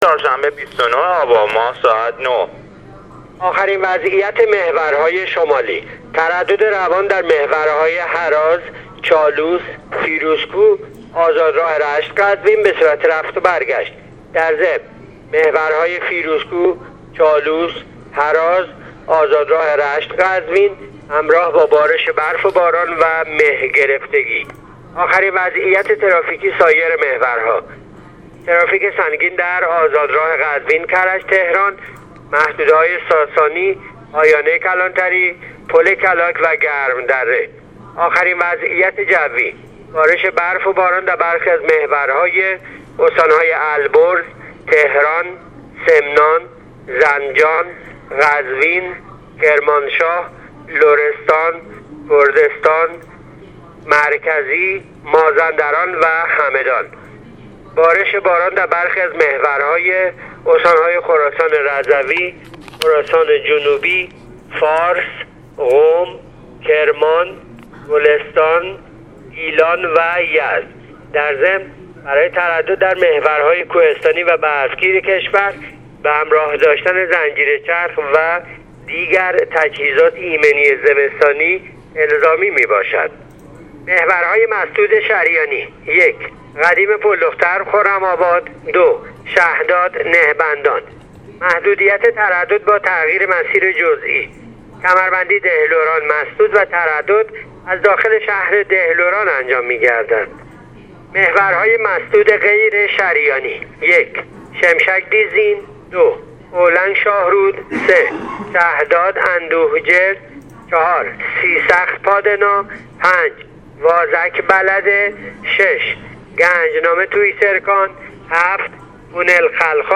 گزارش آخرین وضعیت ترافیکی و جوی جاده‌های کشور را از رادیو اینترنتی پایگاه خبری وزارت راه و شهرسازی بشنوید.